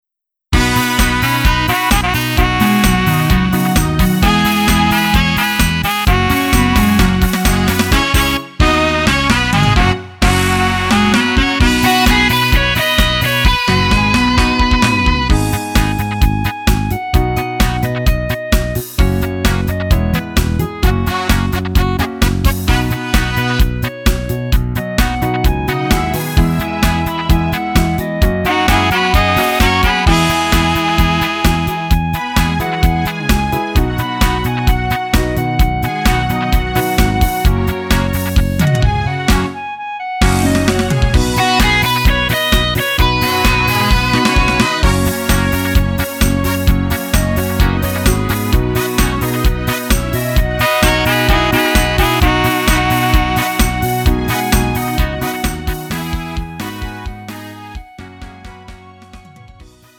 음정 -1키 3:15
장르 구분 Lite MR